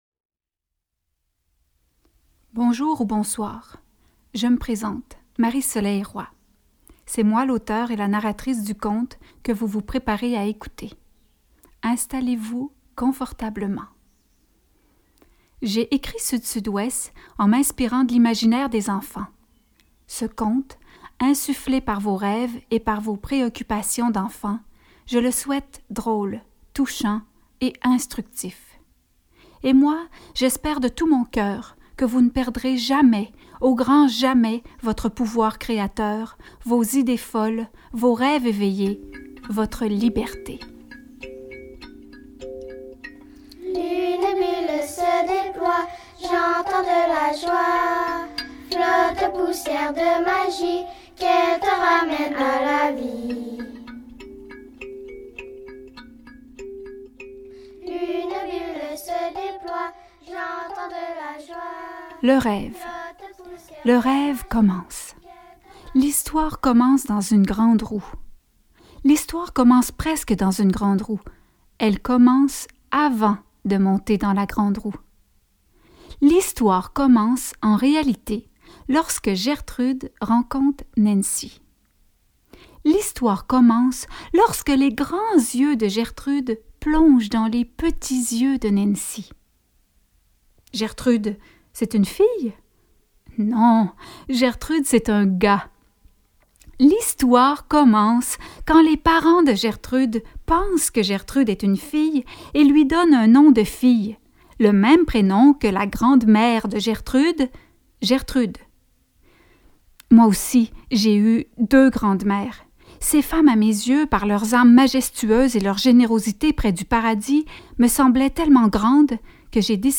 Extrait de la narration du livre